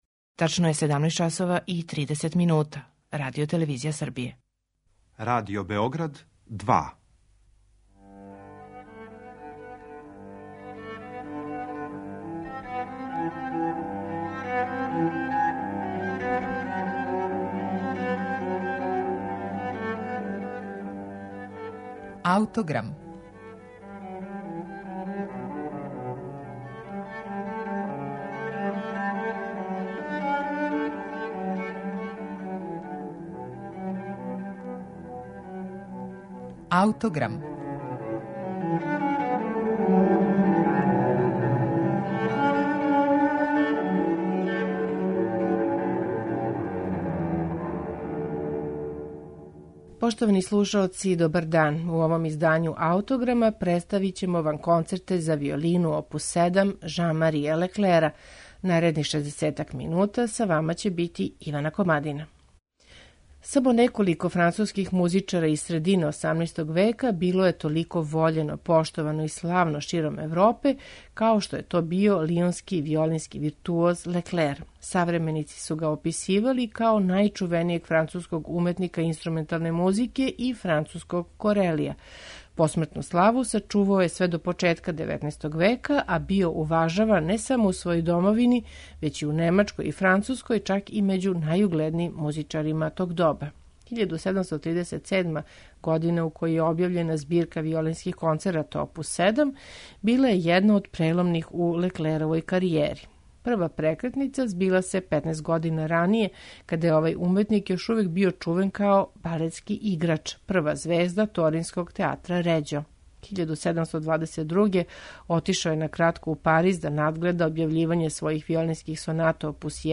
виолинисте